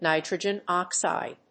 アクセントnítrogen óxide